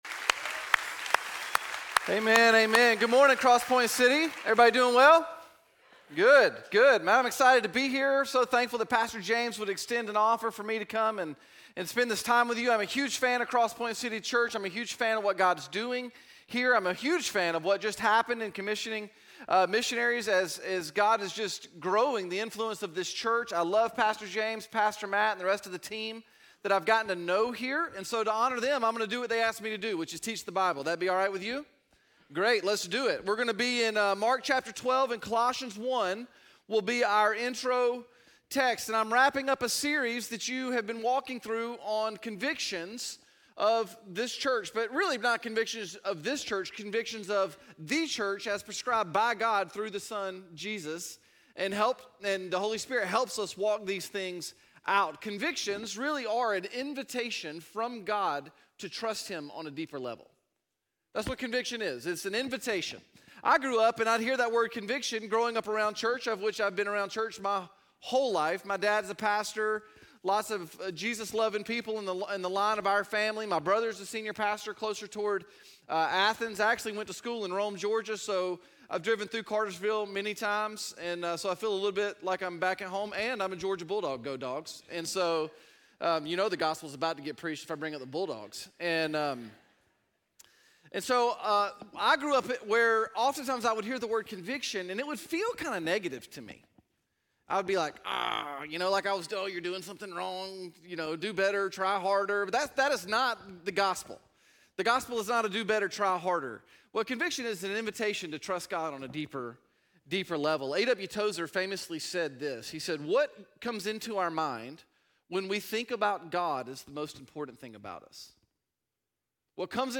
This message is from week 8 of our SUMMER series: "Giving Changes People" We believe our God is a generous God.